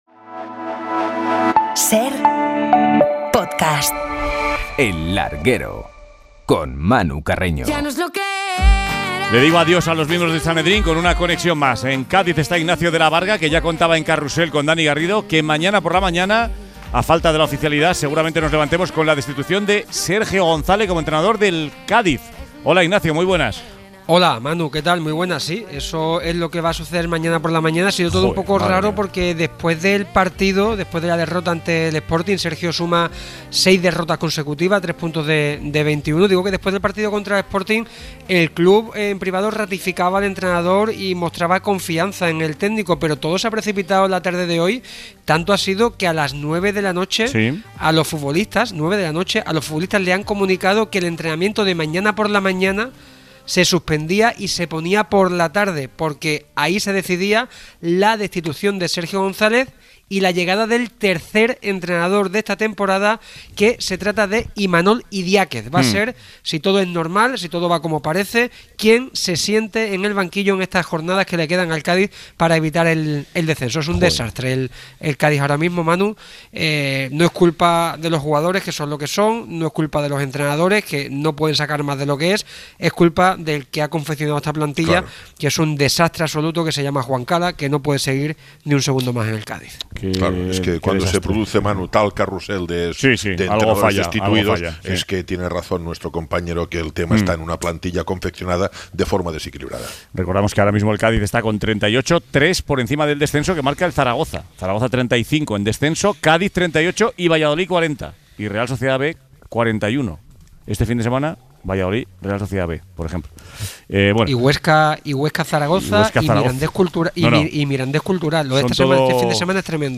Previas de Atlético de Madrid y Barça y entrevista con Elisa Aguilar
Preparamos los partidos del Atlético de Madrid y el FC Barcelona de esta jornada intersemanal. Además, charlamos con la presidenta de la Federación española de Baloncesto, Elisa Aguilar.